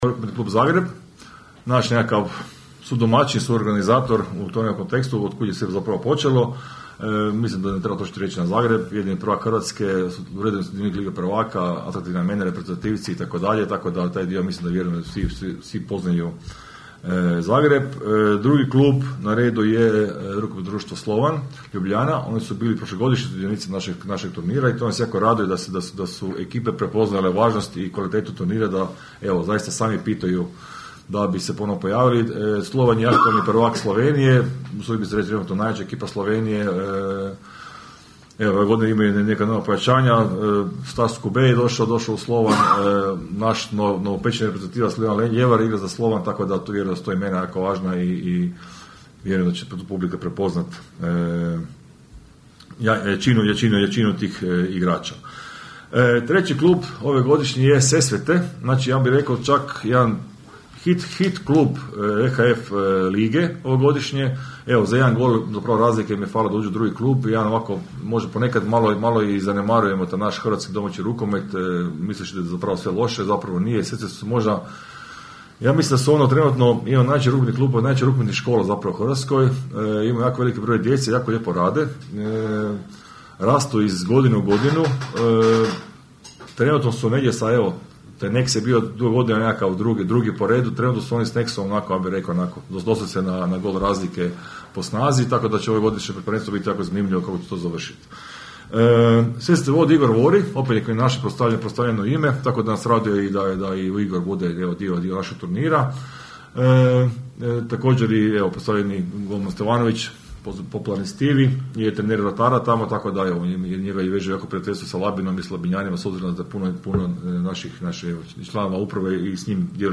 Na današnjoj konferenciji za novinare predstavljen je četvrti međunarodni rukometni turnir Labin Handball Cup, koji će se održati 30. i 31. siječnja iduće godine.